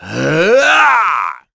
Crow-Vox_Casting3.wav